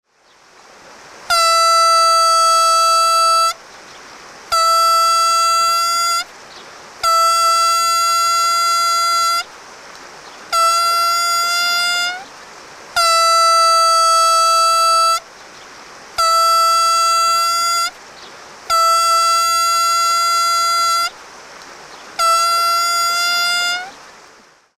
Instrumentos de músicaARTO-TONTORRA
Aerófonos -> Lengüetas -> Libre
Grabado con este instrumento.